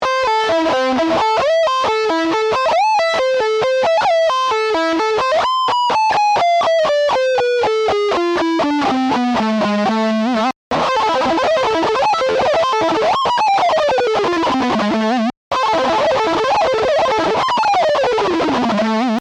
In this case, the two guitar techniques used are sweep picking and directional picking.  In addition, there is a fast position shift required for your fretting hand in order to transition from playing arpeggios to the scale sequence.
Guitar Solo Tab